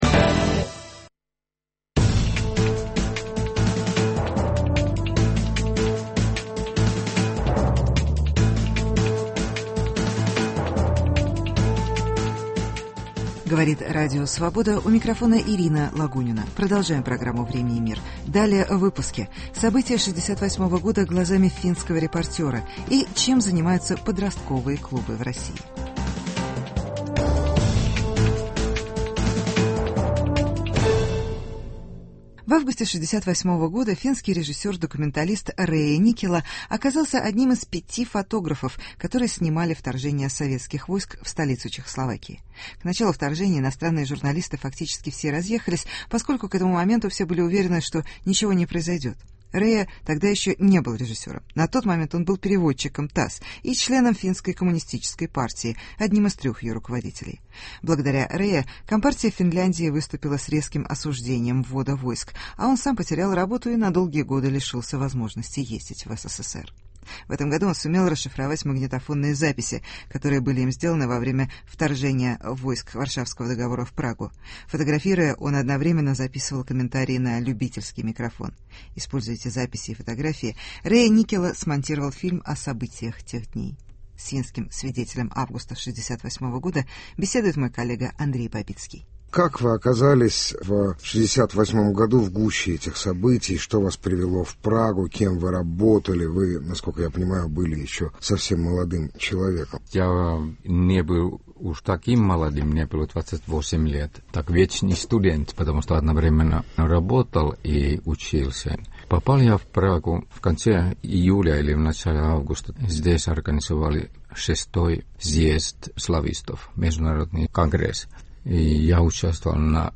Интервью с финским документалистом, снимавшим вторжение в Прагу, ч.1.